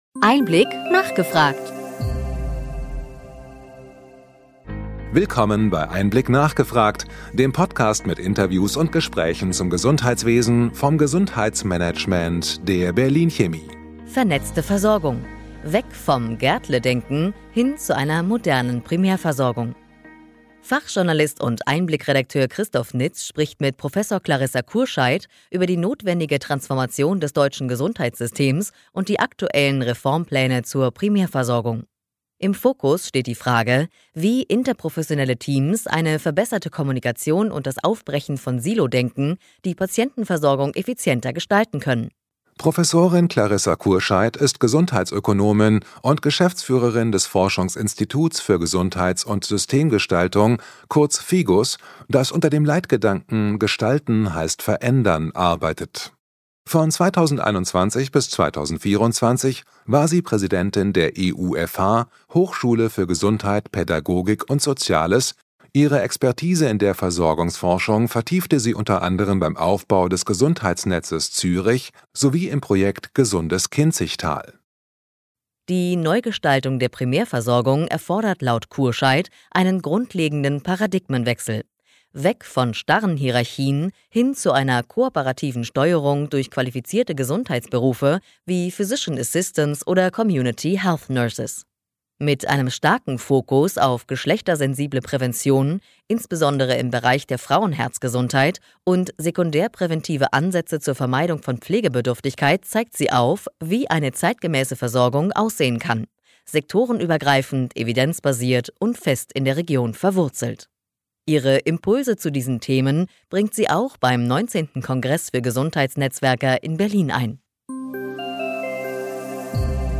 EinBlick – nachgefragt Podcast mit Interviews und Diskussionsrunden mit Expert:innen des Gesundheitswesens